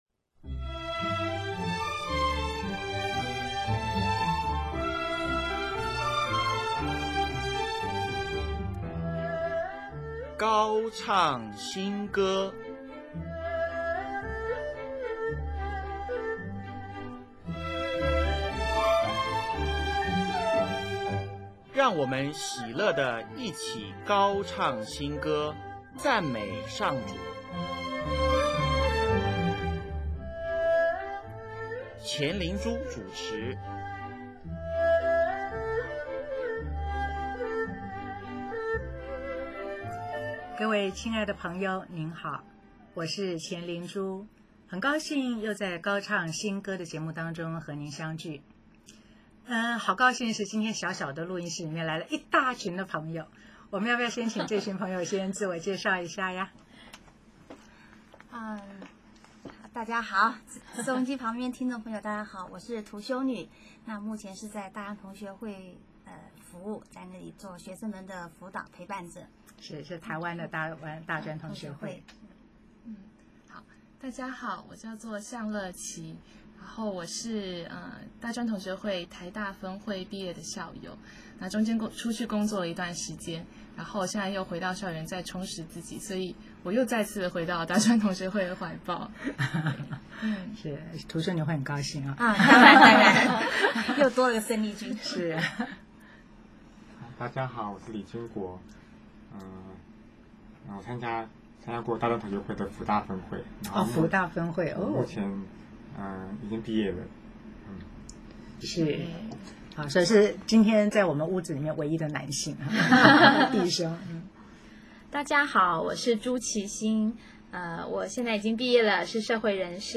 本集播放： “主！光耀你的名”，轻鬆活泼，歌词出自圣咏第八首。 “圣神的歌咏”，现场演唱。 “我信”，歌词採用主日弥撒的信经第二式，在沉稳和宁静中有股坚定的信念在内。